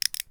Add lockpick sounds
locks_pick.3.ogg